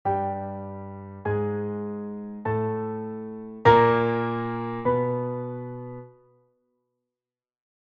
der Ton Ais, Notation
der-Ton-Ais.mp3